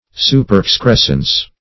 Superexcrescence \Su`per*ex*cres"cence\, n. Something growing superfluously.